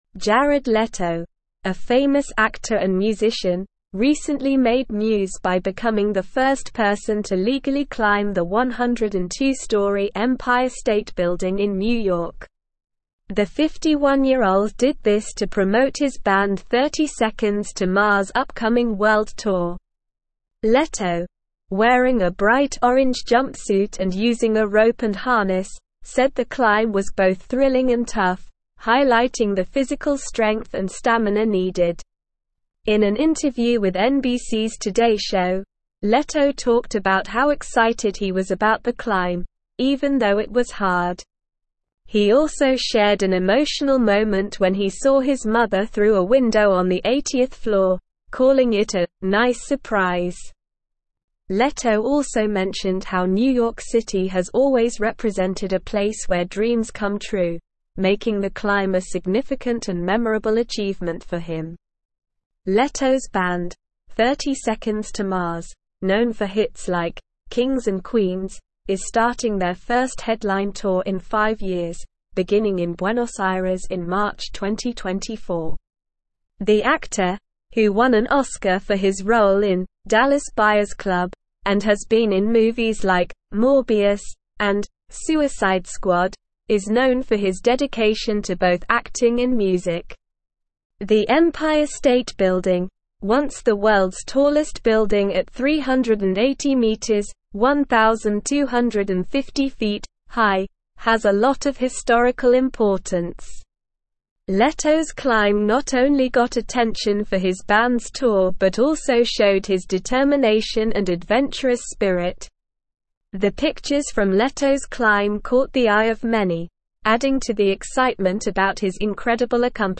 Slow
English-Newsroom-Upper-Intermediate-SLOW-Reading-Jared-Leto-Scales-Empire-State-Building-for-Band.mp3